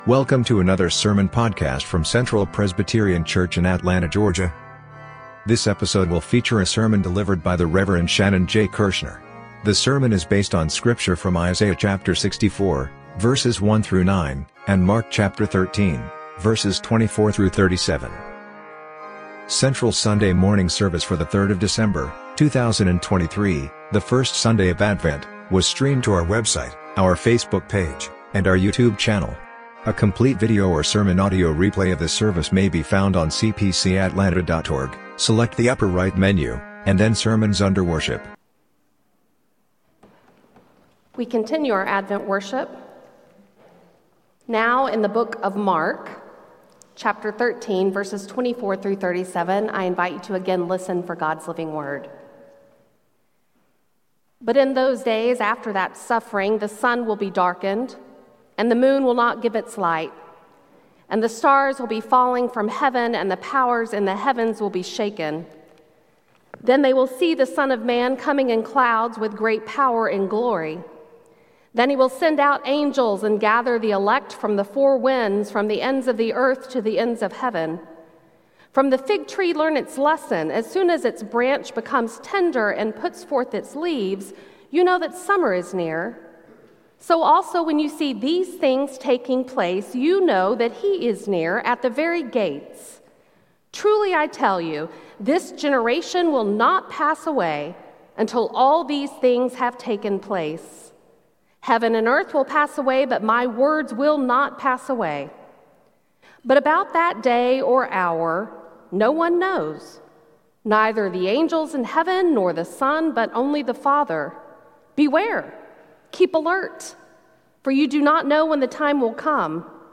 Sermon Audio: